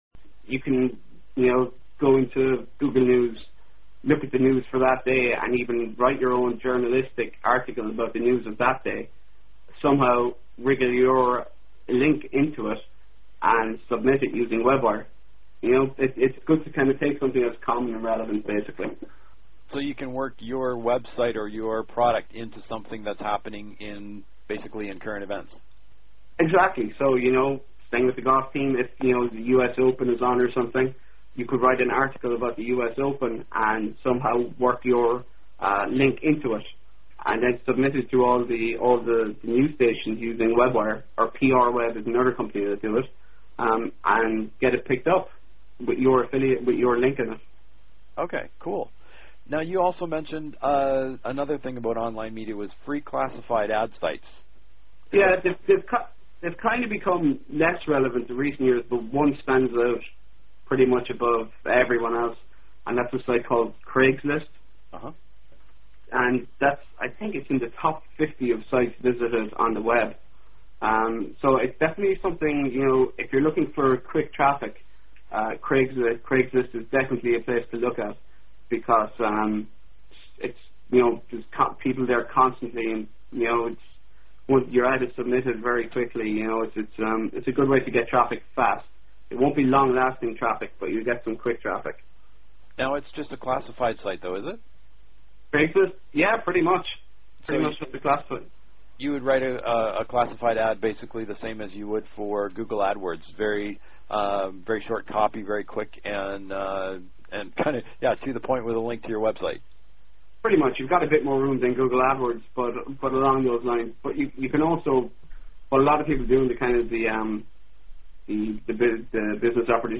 Web Marketing Explained! The Tell All Interview!